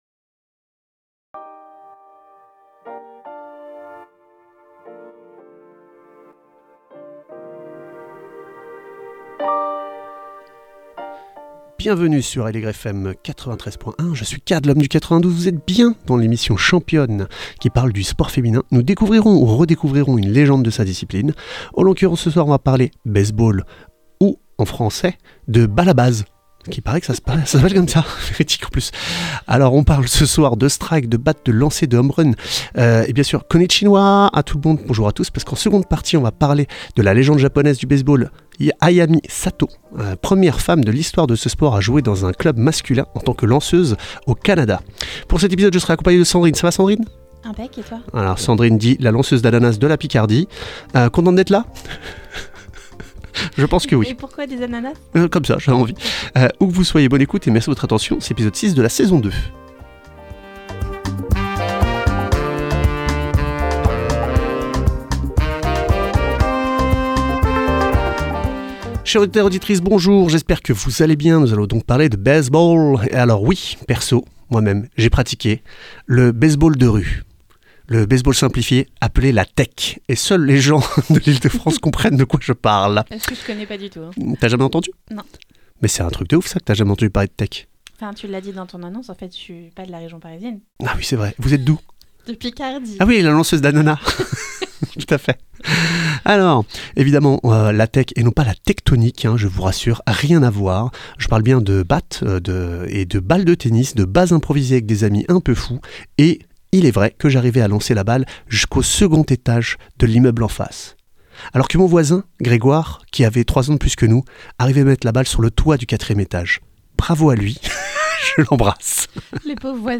Qu’elles soient amatrices, semi-pros ou pros, nous échangeons avec des invitées inspirantes, qui partagent leurs visions et leurs expériences.